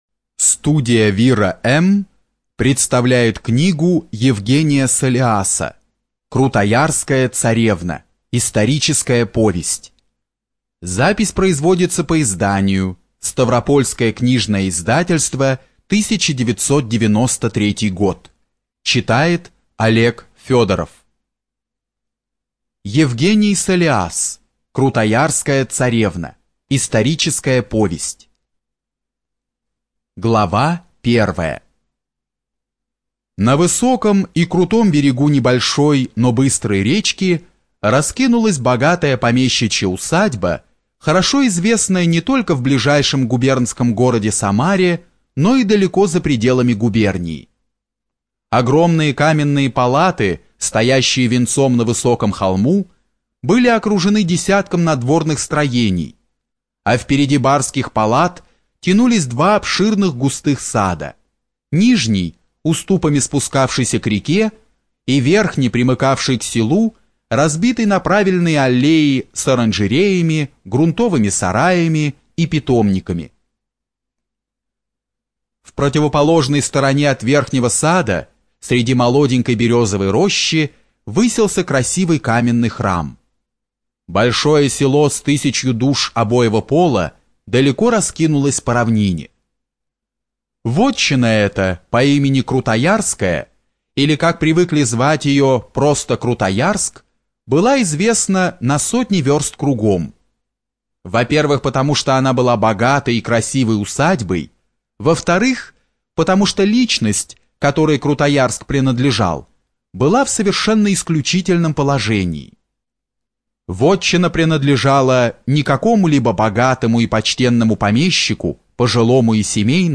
ЖанрИсторическая проза
Студия звукозаписиВира-М